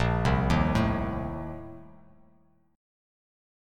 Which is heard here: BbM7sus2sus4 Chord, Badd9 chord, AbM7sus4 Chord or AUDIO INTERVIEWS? BbM7sus2sus4 Chord